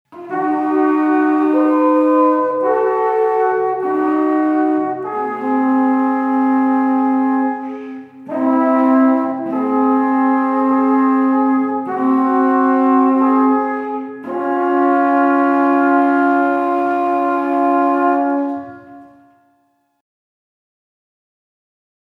Musikalische Leitung: 2 Alphörner, 1 Schlagzeug, 1 Posaune